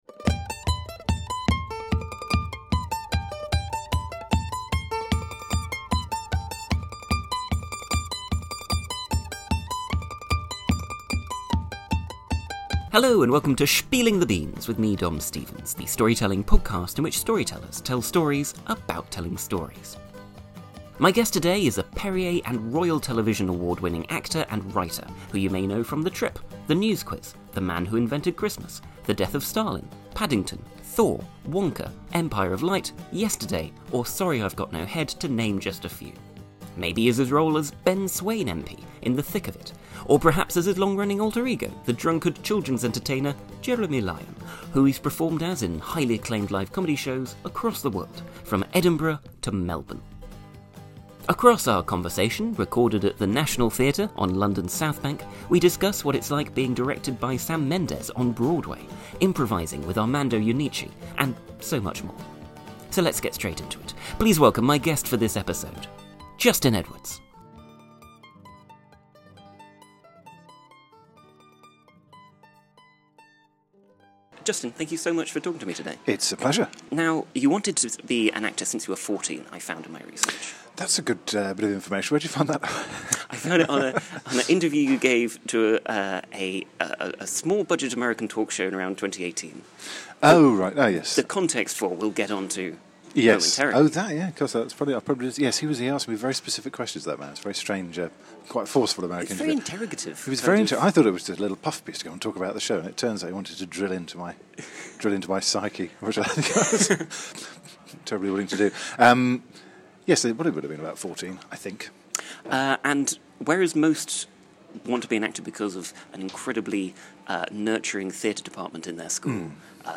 My guest this episode is the Edinburgh Perrier and Royal Television Society Award-winning actor and writer, Justin Edwards.
On being directed by Sam Mendes on Broadway, improvising with Armando Iannucci, comedy sketch writing, and so much more. Recorded at The National Theatre, London, in October 2023.